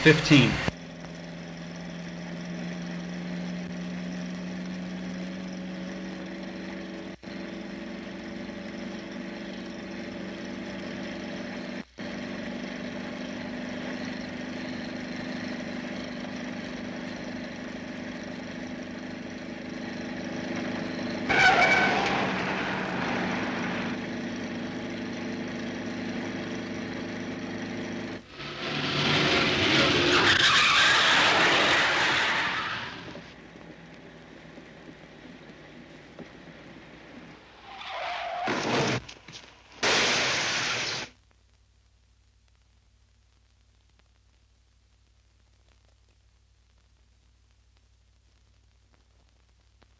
古典碰撞 " G2623搏击混战
描述：在木制的soundstage地板上打架扭打与木椅和家具打破。没有声音。 这些是20世纪30年代和20世纪30年代原始硝酸盐光学好莱坞声音效果的高质量副本。 40年代，在20世纪70年代早期转移到全轨磁带。我已将它们数字化以便保存，但它们尚未恢复并且有一些噪音。
标签： 崩溃 复古